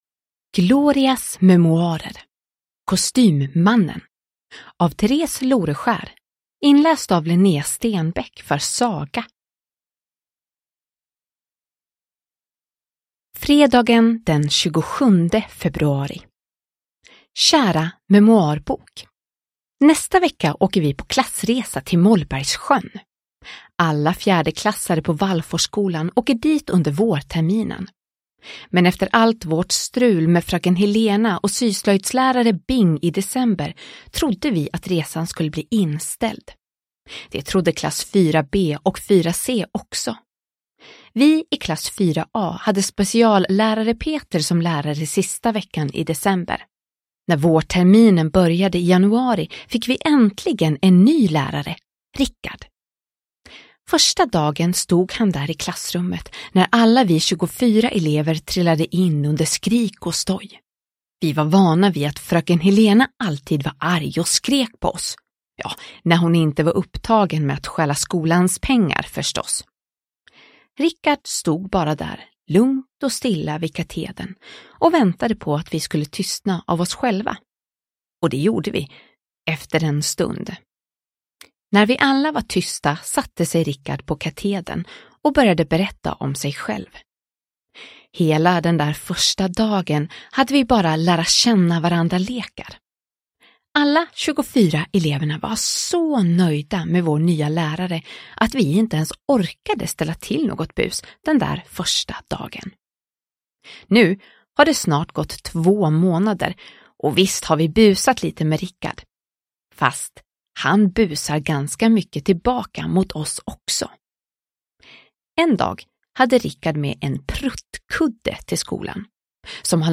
Glorias memoarer: Kostymmannen – Ljudbok – Laddas ner